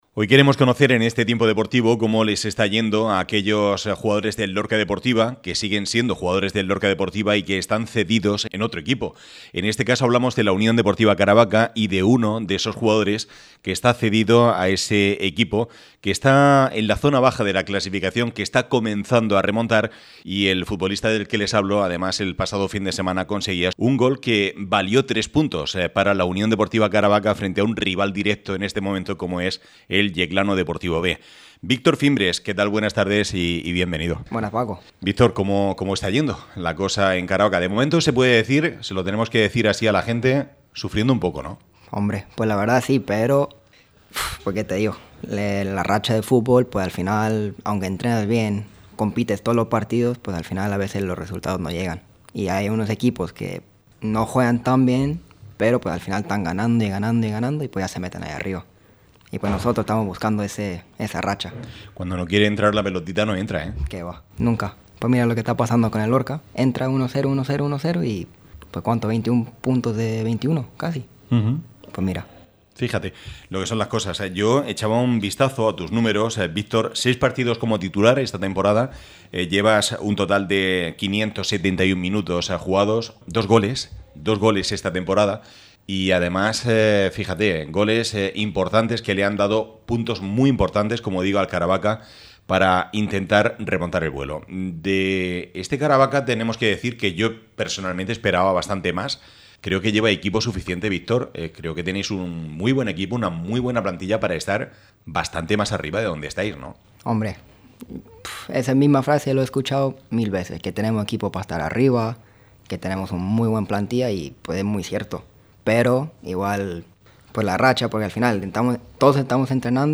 entrevista
programa deportivo